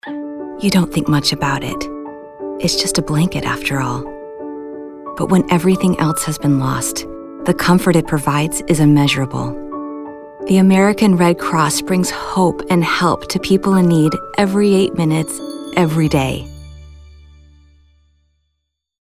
7. Red-Cross (Compassionate).mp3
Red-Cross (Compassionate).mp3